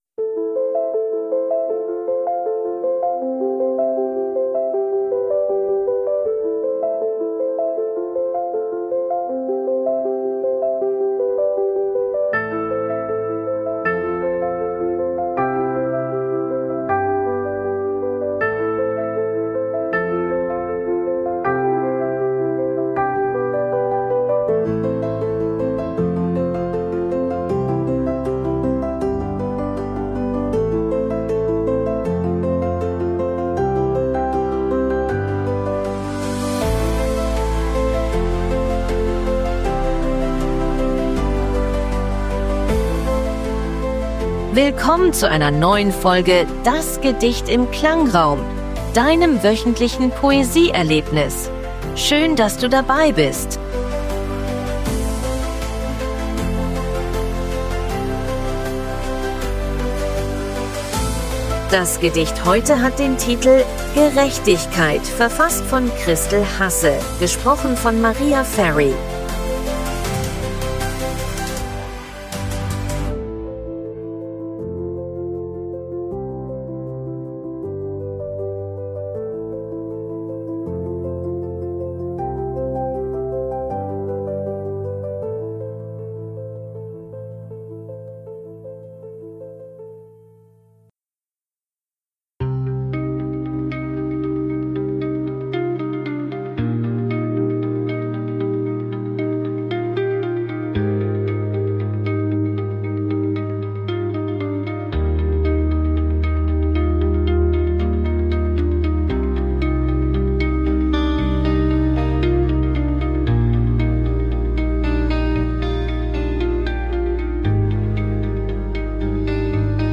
stimmungsvollen Klangkulisse.
begleitet von KI-generierter Musik.